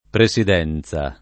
presidenza [ pre S id $ n Z a ] s. f.